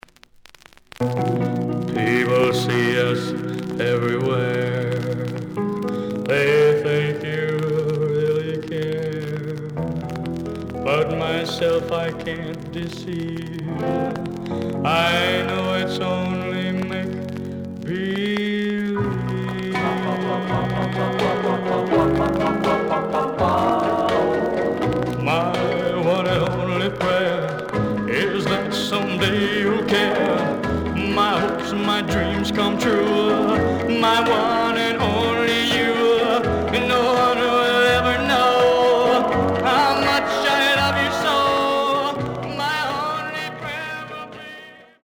The listen sample is recorded from the actual item.
●Format: 7 inch
●Genre: Rhythm And Blues / Rock 'n' Roll